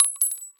bullet-metal-casing-drop-3.mp3